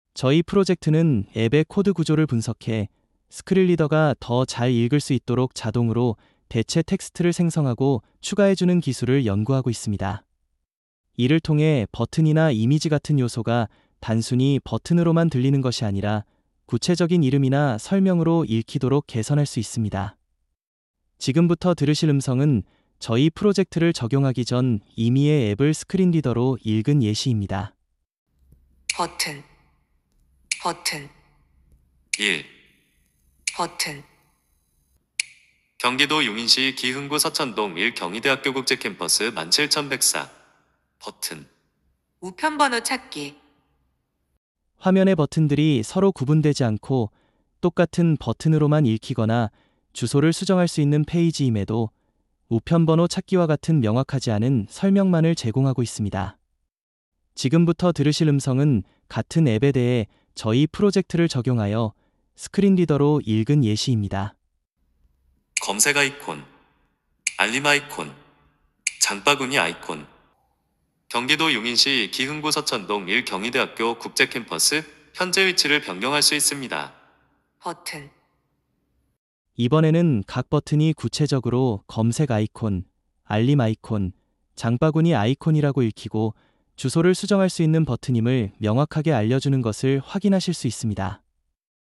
프로젝트 개요 음성.mp3
이번 연구에서는, 실제 앱 화면을 스크린리더로 읽었을 때 프로젝트 적용 전과 후의 차이를 들어보신 뒤, 프로젝트에 대한 의견을 부탁드리고자 합니다.